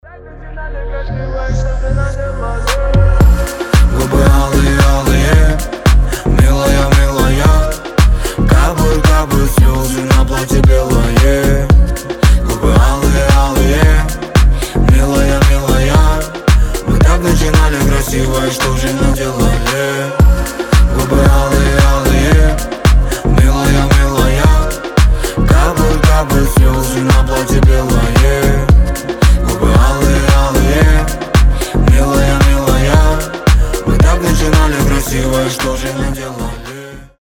поп
грустные
дуэт